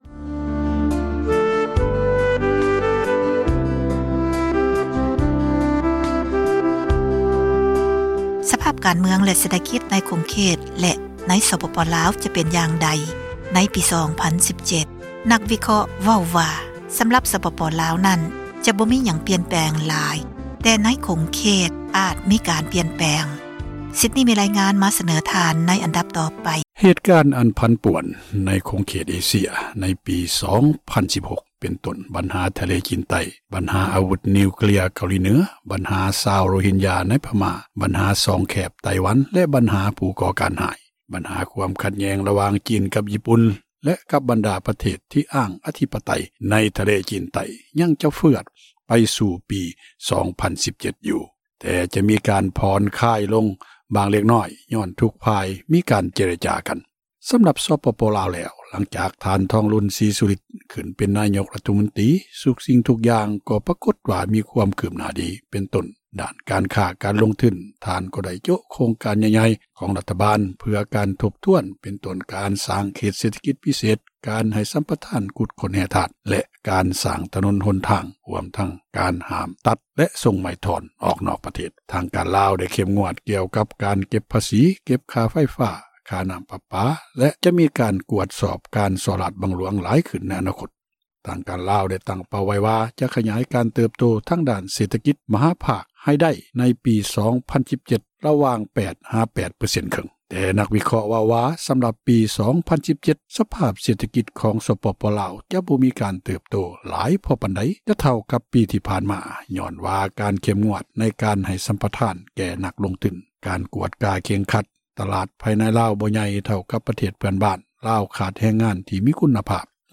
ມີຣາຍງານ